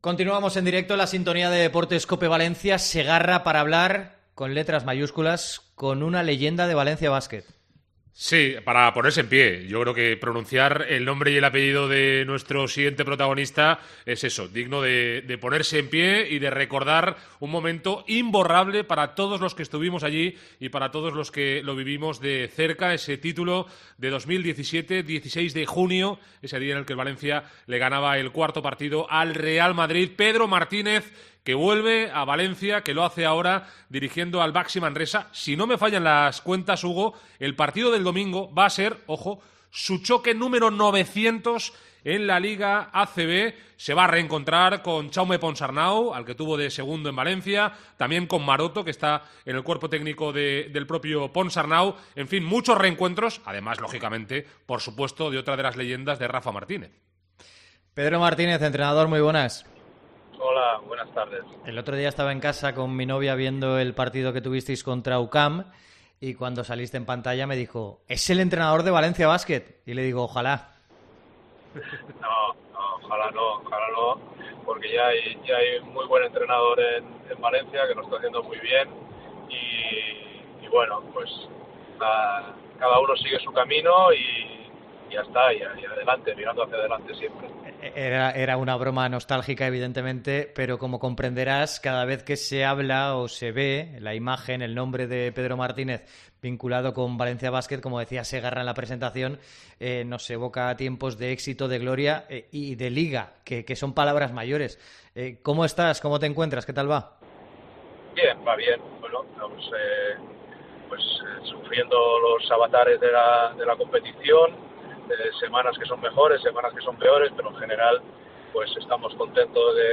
ENTREVISTA COPE
Pedro Martínez en una entrevista en COPE